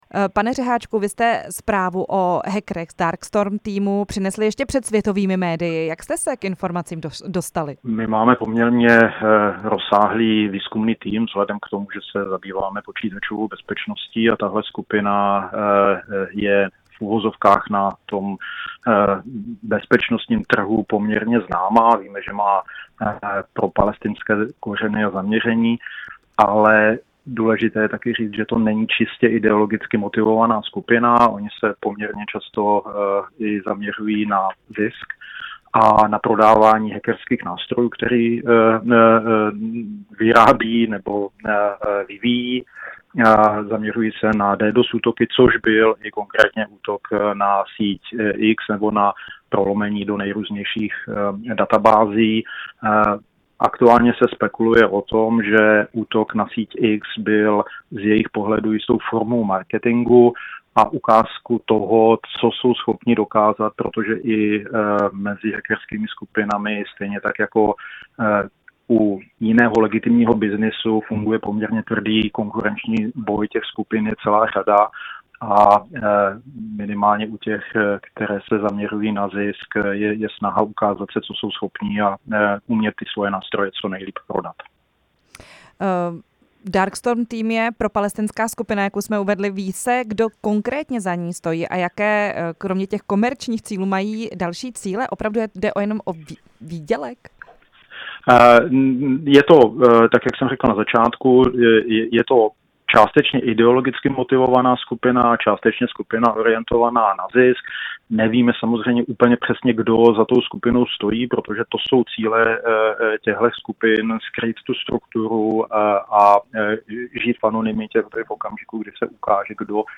Rozhovor s odborníkem na kyberbezpečnost